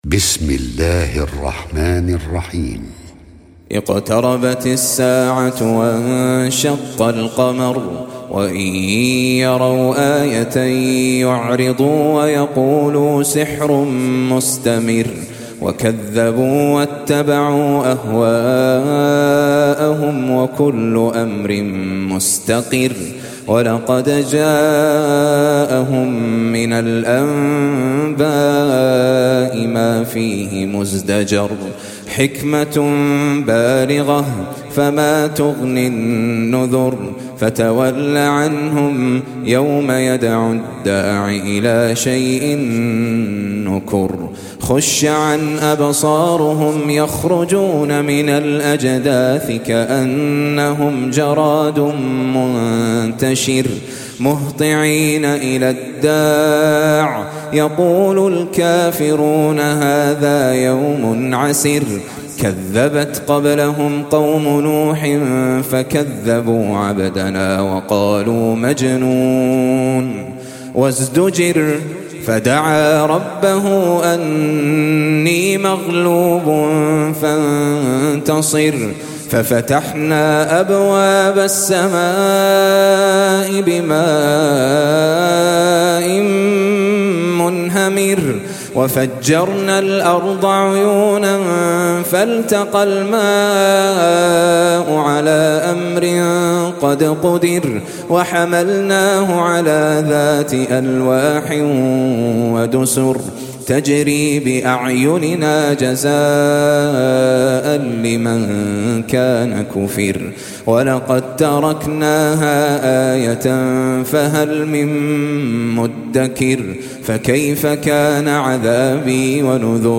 54. Surah Al-Qamar سورة القمر Audio Quran Tarteel Recitation
Surah Sequence تتابع السورة Download Surah حمّل السورة Reciting Murattalah Audio for 54.